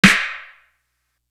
Calibre Clap.wav